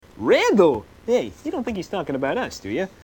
Category: Comedians   Right: Personal